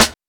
Csp_Snr1.wav